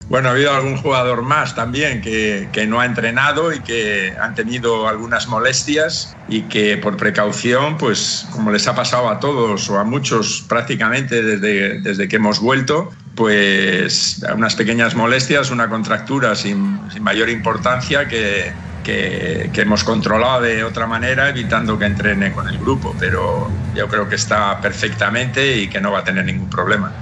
Quique-Setién-técnico-del-Barcelona.mp3